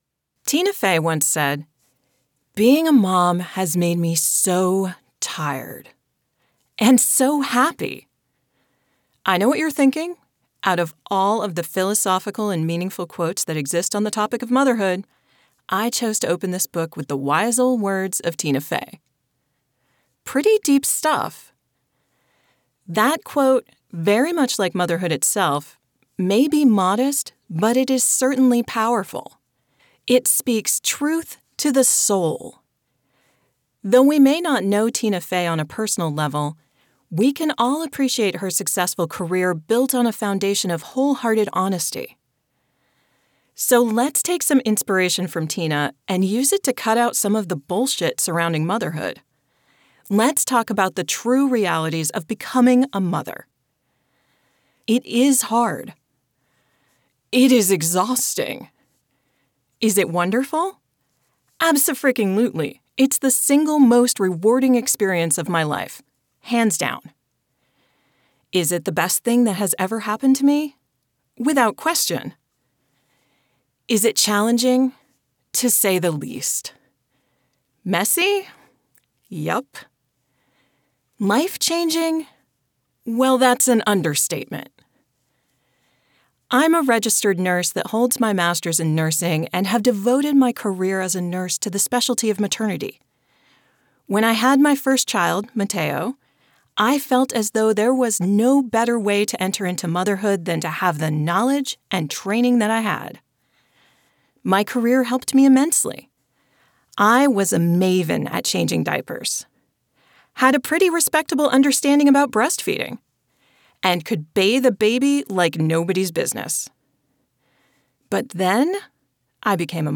A comfortable, friendly, intelligent delivery that the listener enjoys.
Audiobook Narration
memoir demo download
Neutral accent